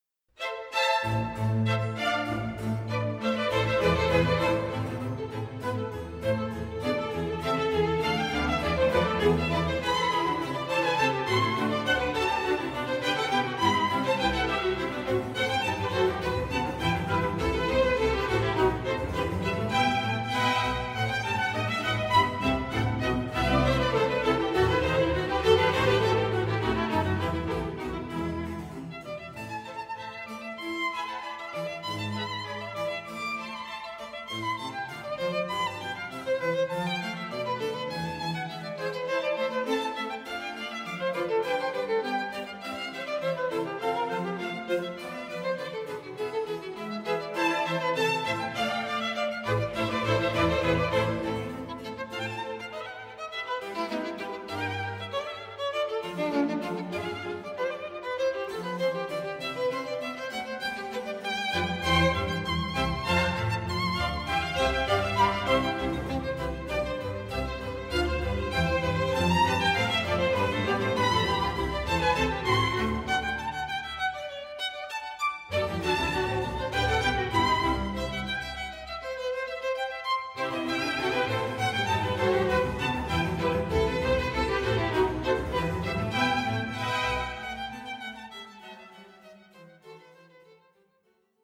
Violin Concerto No. 1 in A minor BWV 1041 1 Allegro moderato (1958 Version)